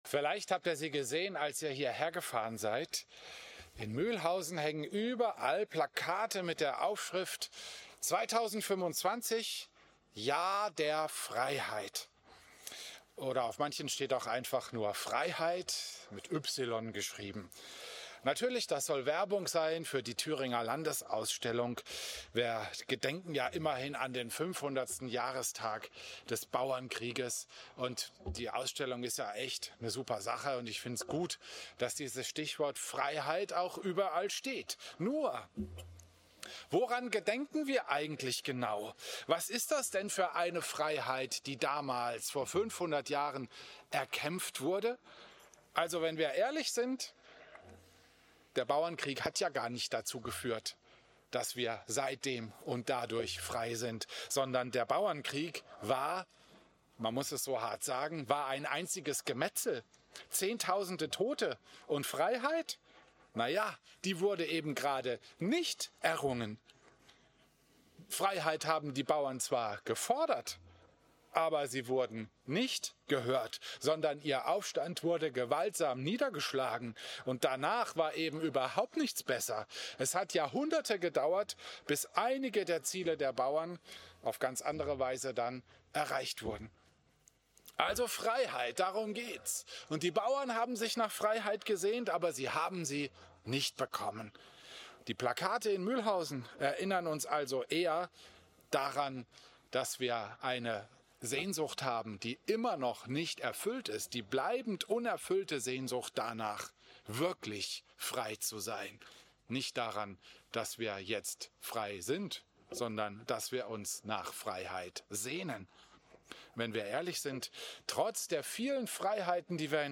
Predigt
Christus-Pavillon Volkenroda, 14.